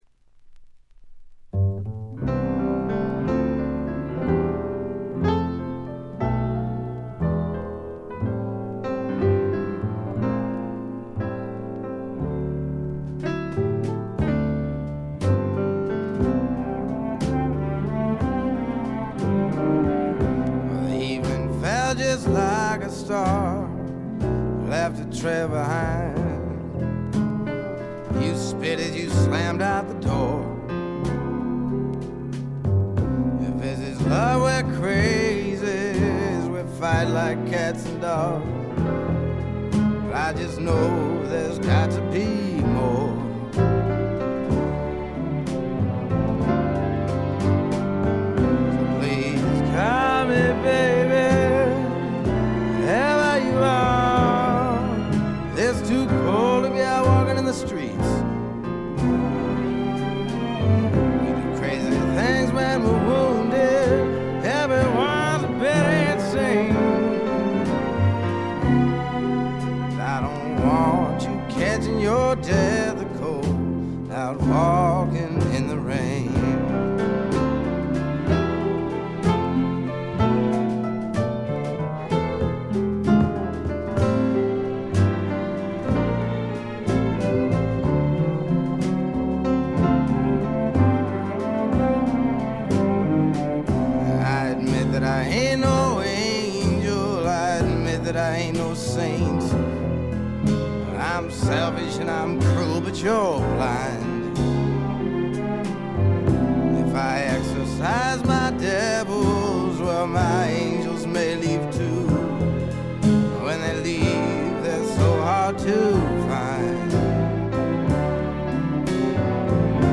軽微なチリプチ程度。
メランコリックでぞっとするほど美しい、初期の名作中の名作です。
試聴曲は現品からの取り込み音源です。
vocals, piano, guitar